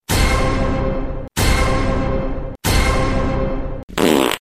Karts start
30-kartz-start.mp3